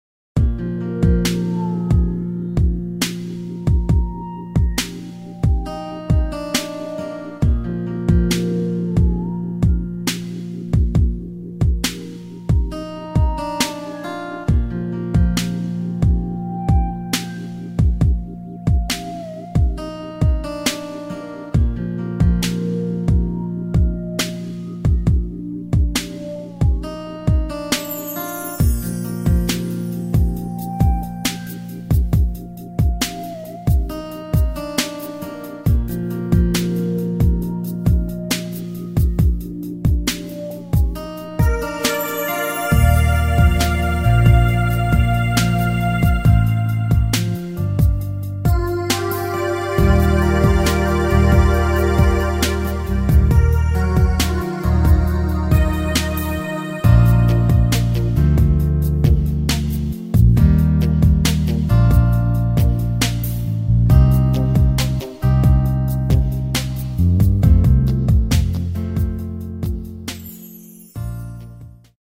Key of B flat
Backing track only.